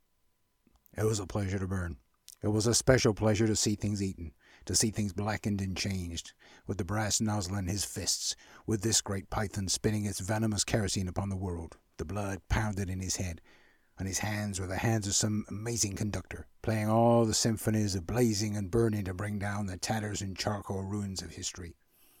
Generic American Narration